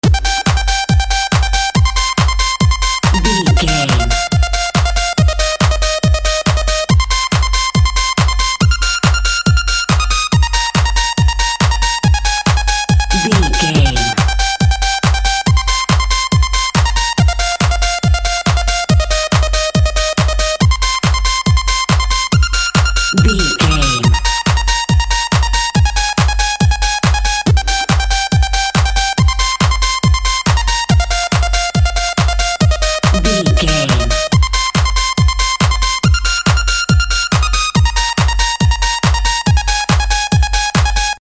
Jamming Techno.
Aeolian/Minor
driving
energetic
high tech
futuristic
hypnotic
synth lead
synth bass
synth drums